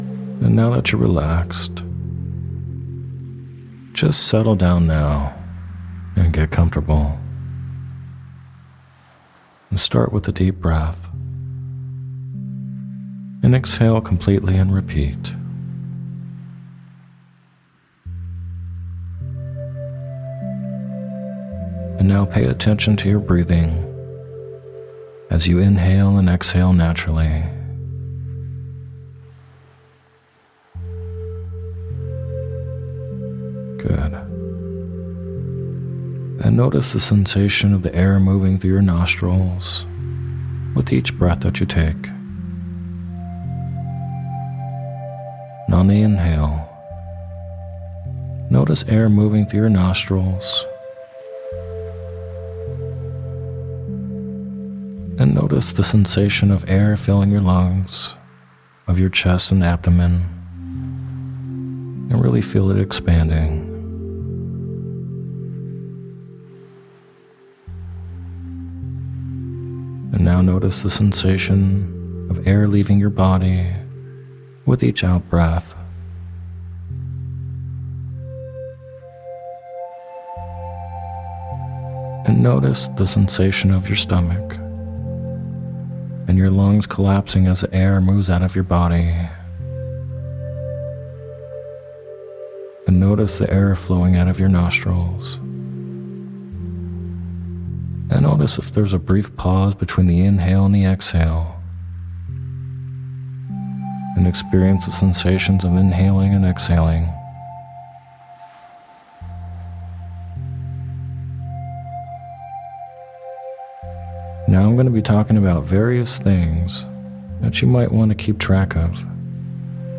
In this guided meditation, or hypnosis audio we’ll do a visualization to help improve your meditation abilities and skills.